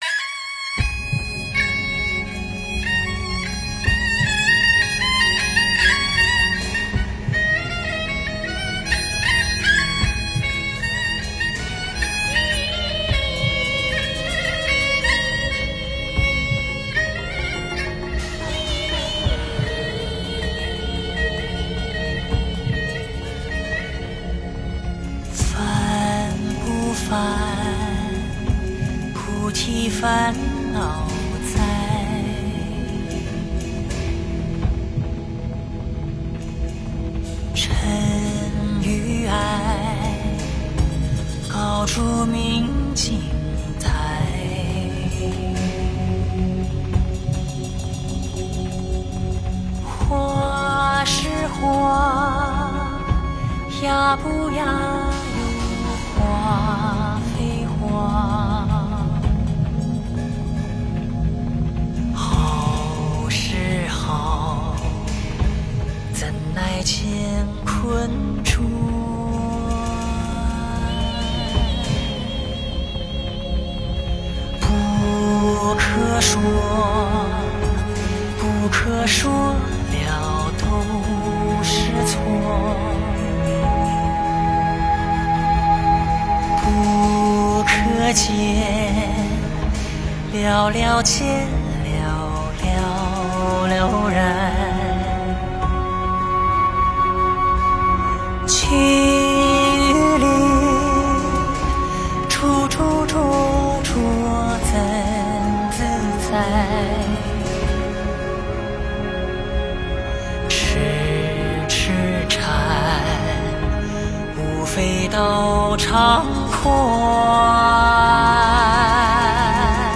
佛音 诵经 佛教音乐 返回列表 上一篇： 不送帖 下一篇： 卷珠帘 相关文章 三宝歌 三宝歌--群星...